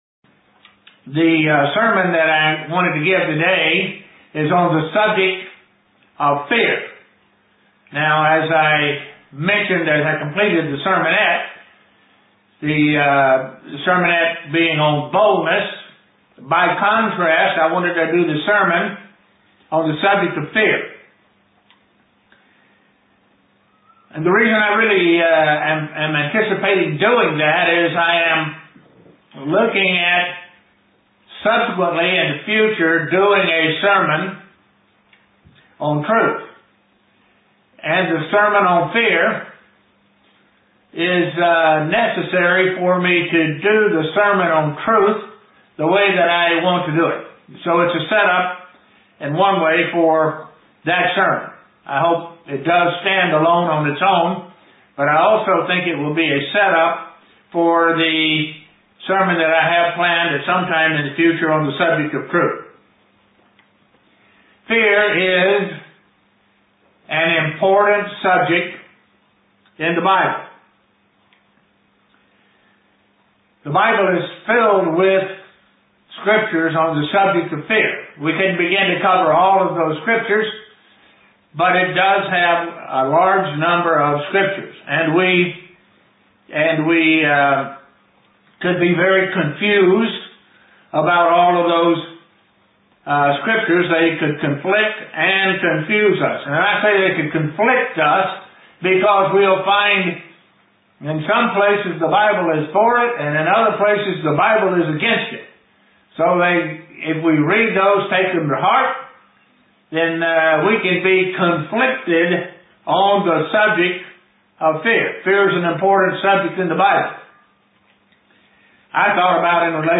What is appropriate or inappropriate fear? The types of fear that are in the Bible. (Broadcast from Estonia)
(Broadcast from Estonia) UCG Sermon Studying the bible?